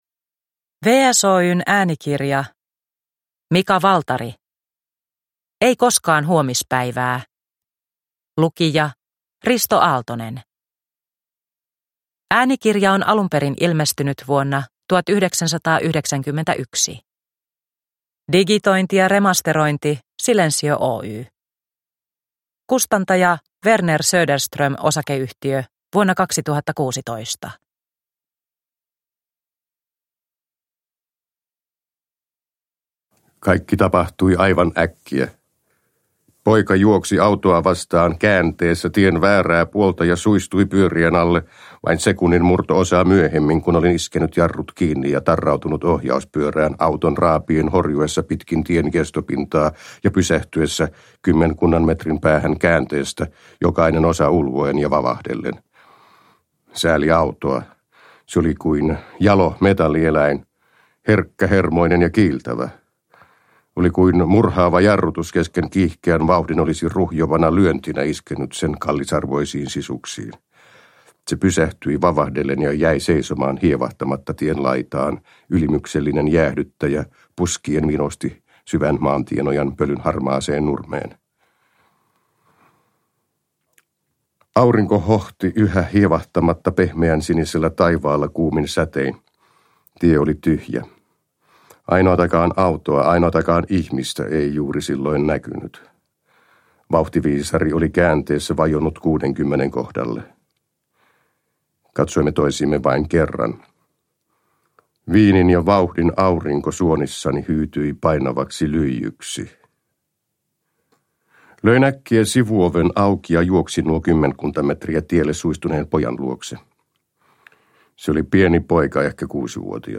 Ei koskaan huomispäivää – Ljudbok – Laddas ner